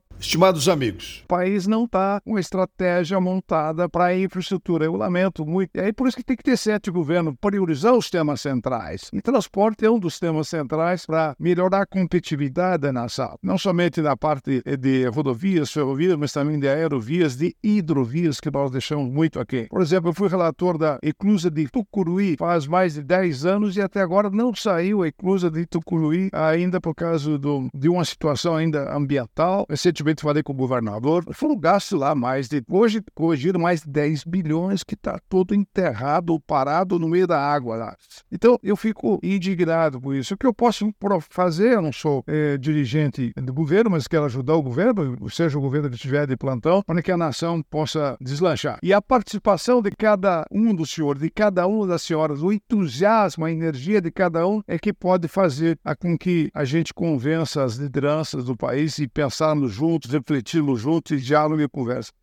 É o assunto do comentário desta quarta-feira (28/08/24) do ministro Augusto Nardes (TCU), especialmente para OgazeteitO.